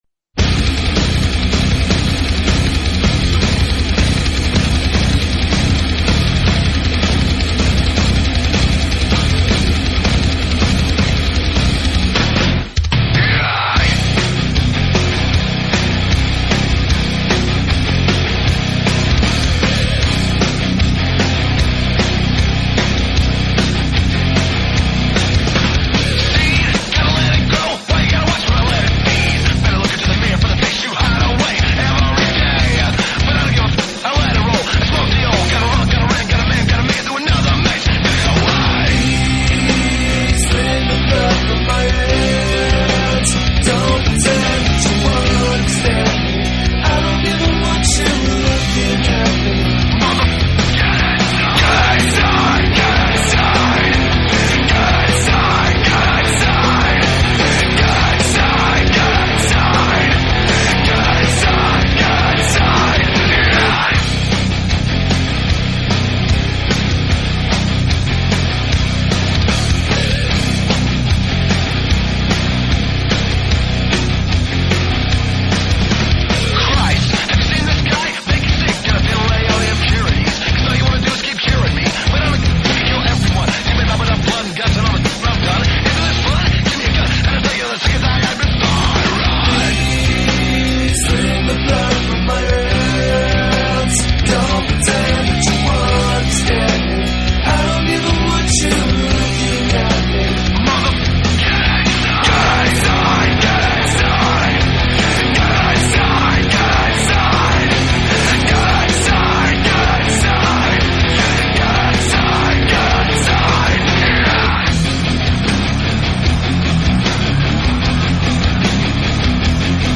Heavy Metal, Alternative Metal